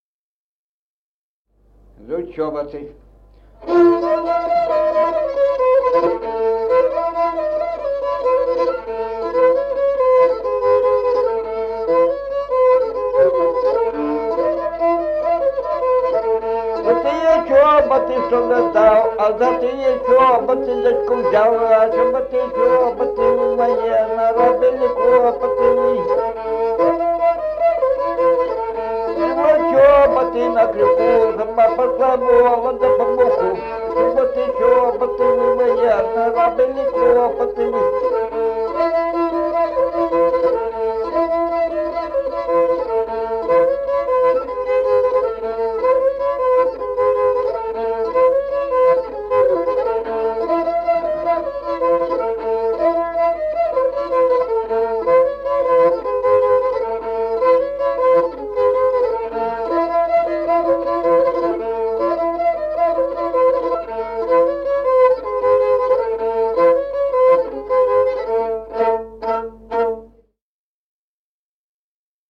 Музыкальный фольклор села Мишковка «Чоботы», репертуар скрипача.